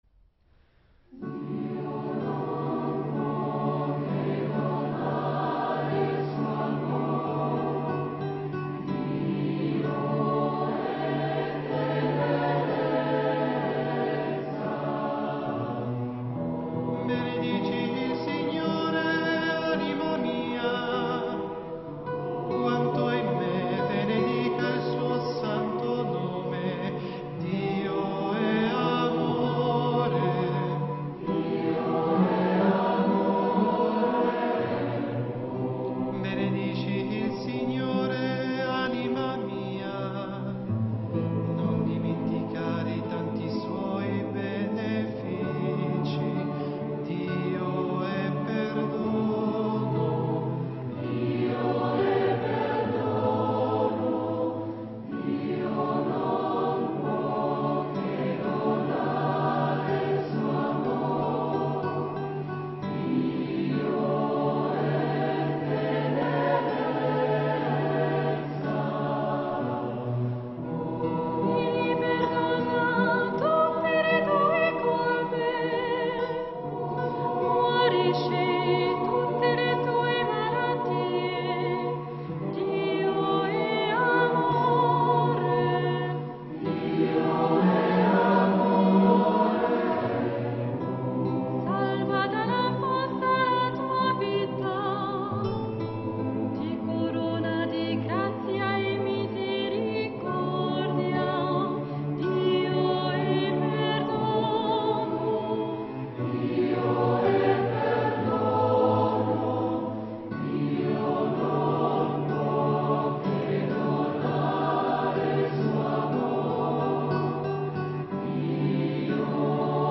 Se vuoi, puoi meditare con il canone di Taizé: "